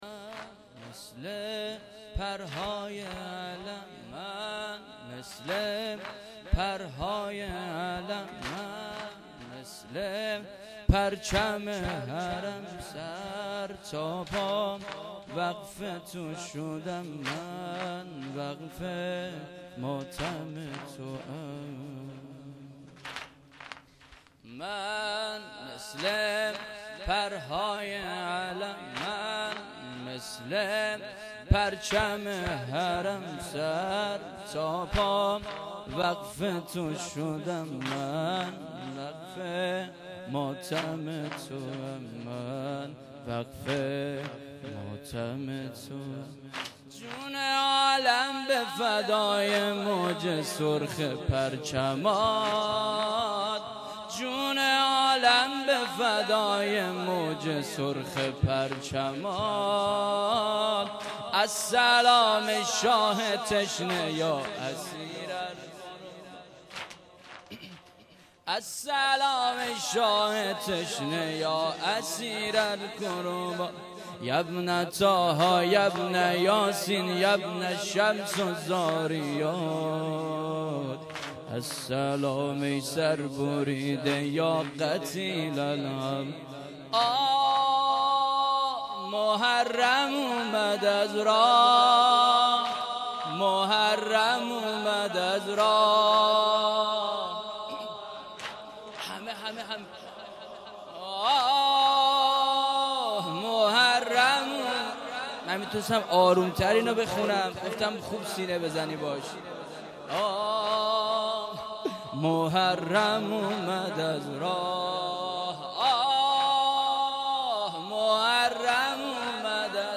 هیئت محبین انصار المهدی(عج)_محرم 1398